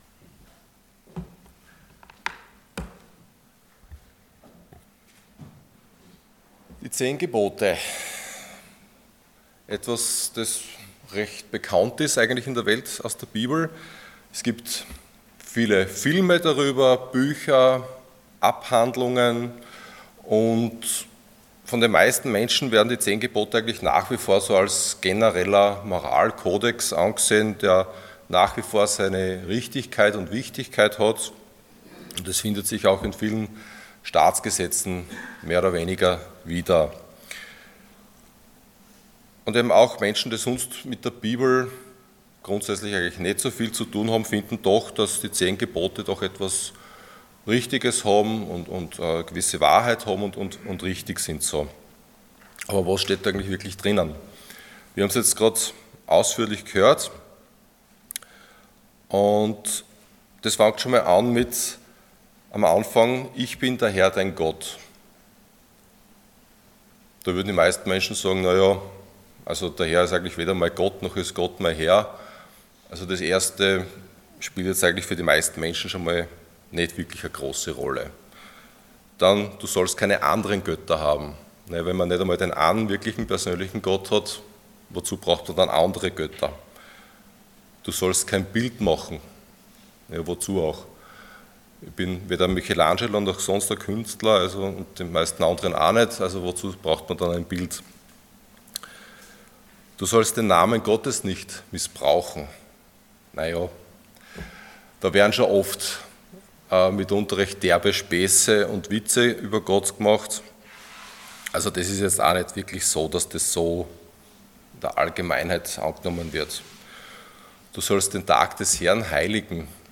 Unterwegs zum Ziel Passage: 2. Mose 20 Dienstart: Sonntag Morgen Die Zehn Gebote Themen: Gebote , Gesetz « Die Gottesbegegnung – Wer wird das überleben?